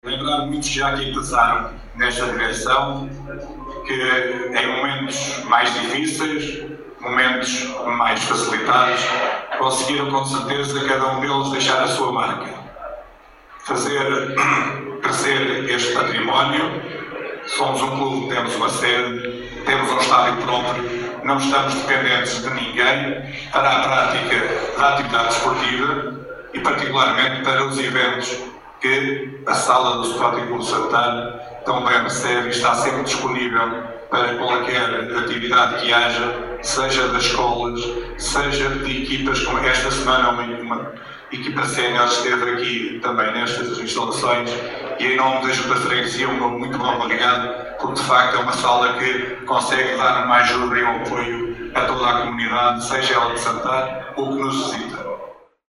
Este domingo, 27 de julho, realizou-se na sede do Sporting Clube de Santar o almoço do 48º aniversário, onde reuniu Associados, Dirigentes, Representante da Associação de Futebol de Viseu, Presidente da União de Freguesias de Santar e Moreira e Presidente da Câmara Municipal de Nelas.